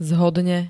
Zvukové nahrávky niektorých slov
hxgd-zhodne.ogg